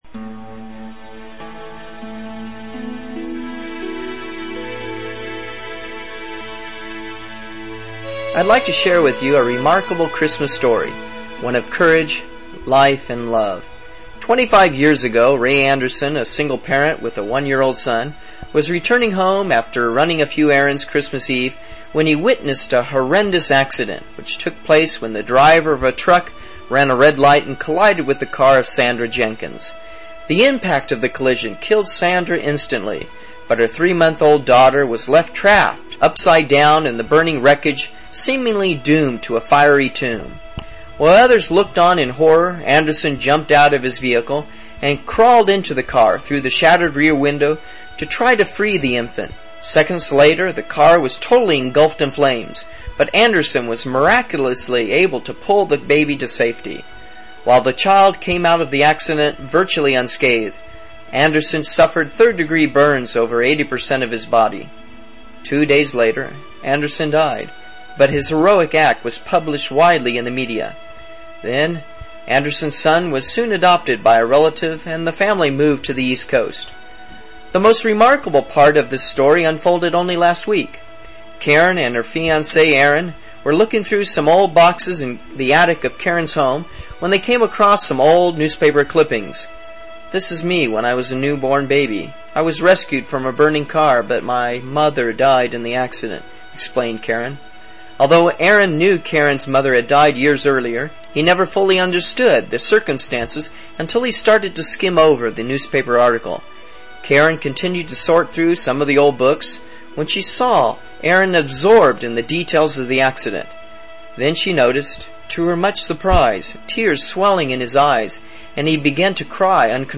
【听英文对话做选择】圣诞节礼物 听力文件下载—在线英语听力室